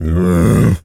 pgs/Assets/Audio/Animal_Impersonations/gorilla_growl_deep_04.wav at master
gorilla_growl_deep_04.wav